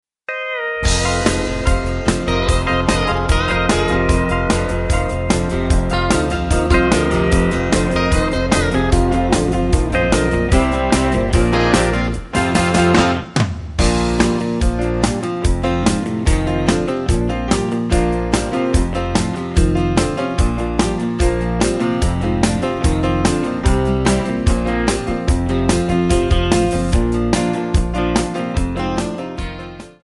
Backing track Karaoke
Country, 1990s